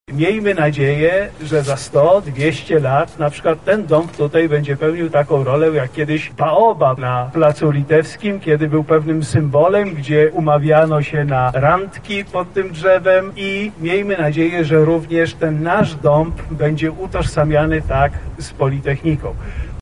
• mówi rektor Politechniki Lubelskiej prof. dr hab. inż. Zbigniew Pater.